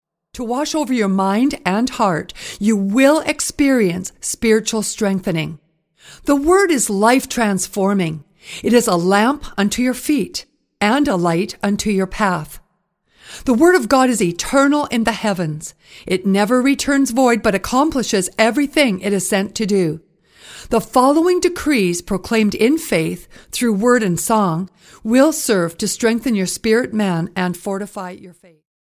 Music CD